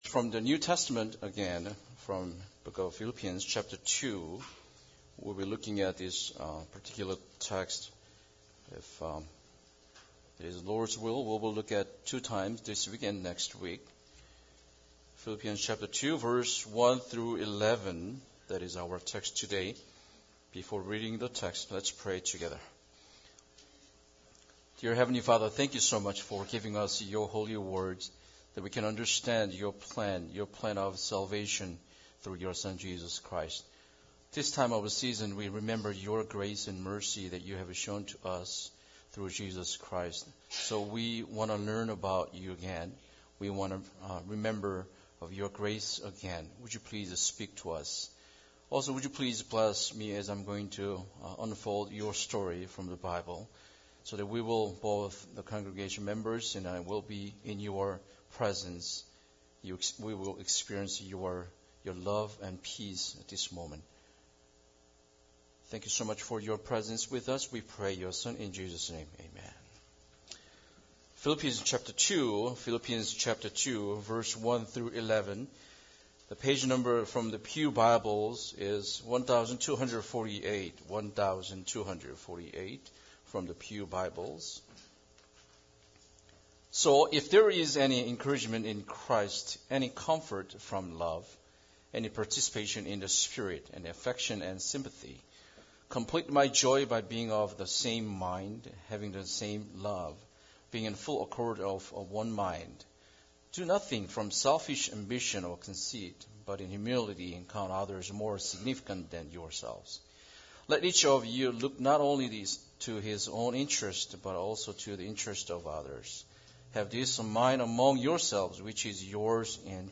Philippians 2:1-11 Service Type: Sunday Service Bible Text